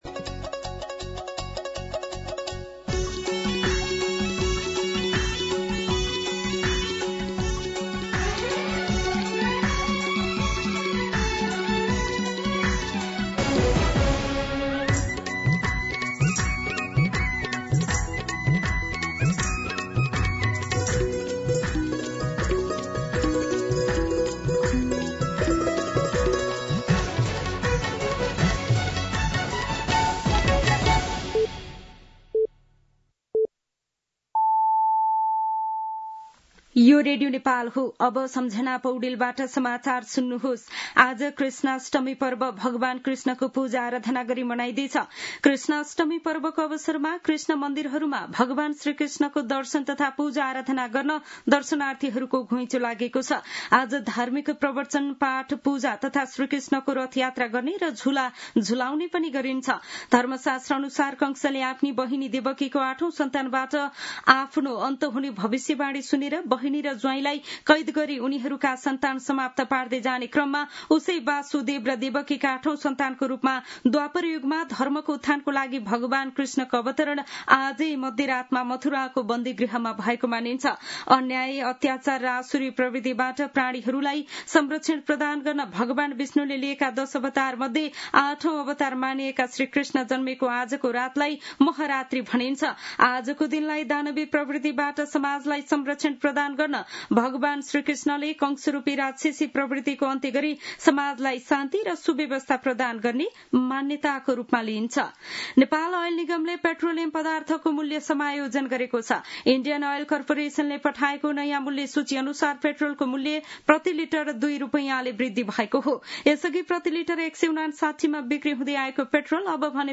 दिउँसो १ बजेको नेपाली समाचार : ३१ साउन , २०८२